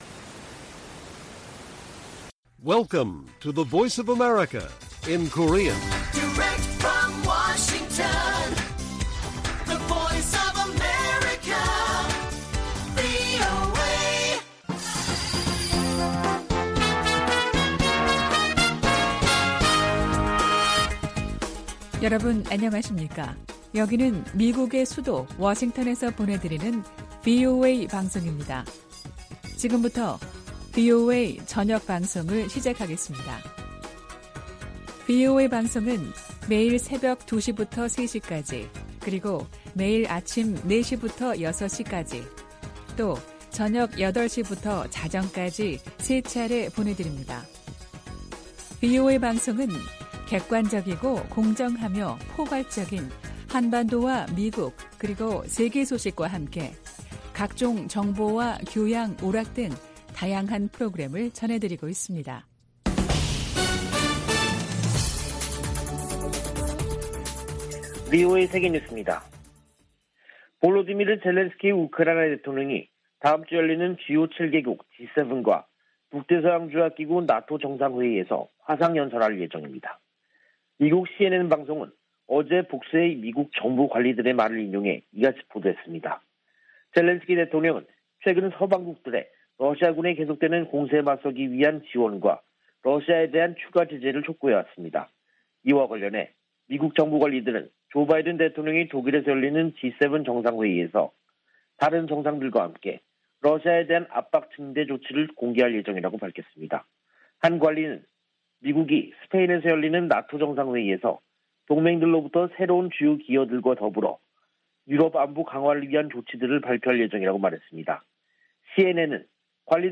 VOA 한국어 간판 뉴스 프로그램 '뉴스 투데이', 2022년 6월 23일 1부 방송입니다. 윤석열 한국 대통령이 이달 말 나토 정상회의에 참석할 예정인 가운데, 미 국무부는 한국을 나토의 중요한 파트너라고 언급했습니다. 나토는 사이버·비확산 분야 등 한국과의 협력 관계 증진에 대한 기대감을 나타냈습니다. 미 하원 군사위원회가 강력한 주한미군 유지 중요성을 강조하는 내용 등이 담긴 새 회계연도 국방수권법안을 공개했습니다.